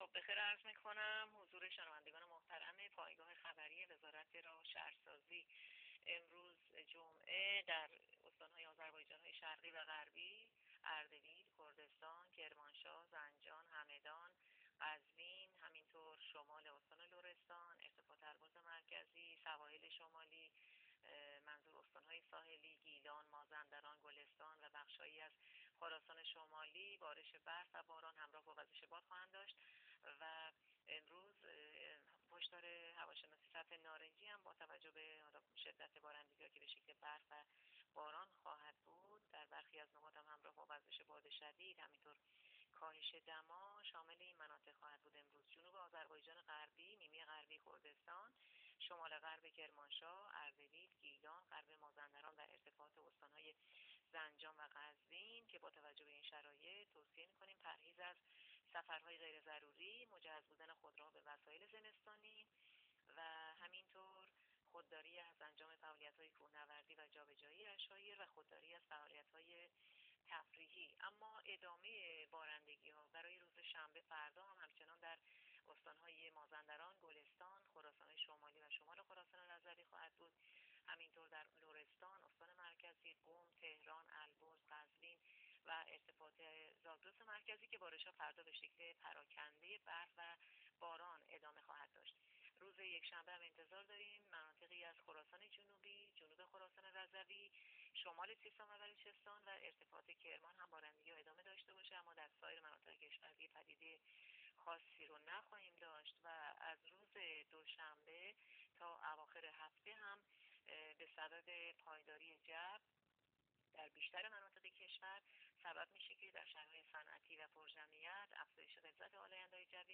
گزارش آخرین وضعیت جوی کشور را از رادیو اینترنتی پایگاه خبری وزارت راه و شهرسازی بشنوید.
گزارش رادیو اینترنتی از آخرین وضعیت آب و هوای دوازدهم دی؛